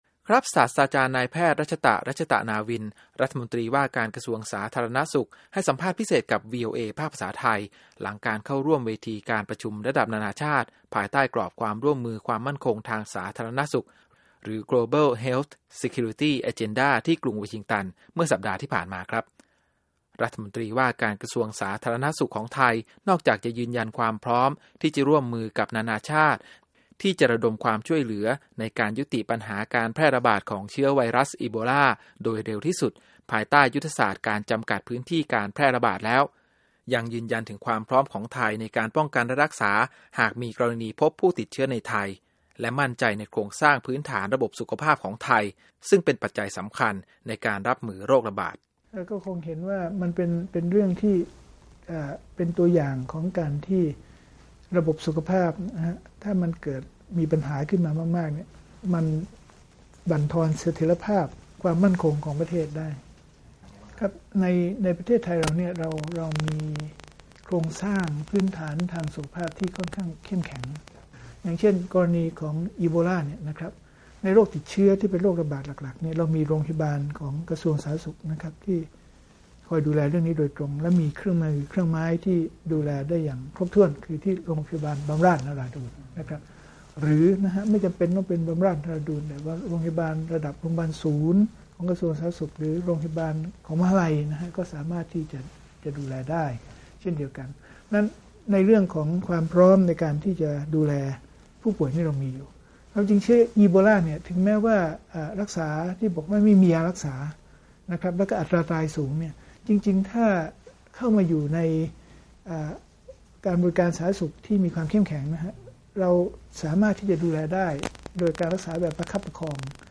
Ebola Interview
ศาสตราจารย์ นายแพทย์ รัชตะ รัชตะนาวิน รัฐมนตรีว่าการกระทรวงสาธารณสุข ให้สัมภาษณ์พิเศษกับวีโอเอ ภาคภาษาไทย หลังการเข้าร่วมเวทีการประชุมระดับนานาชาติ ภายใต้กรอบความร่วมมือความมั่นคงทางสาธารณสุข หรือ Global Health Security Agenda ที่กรุงวอชิงตันเมื่อสัปดาห์ที่ผ่านมา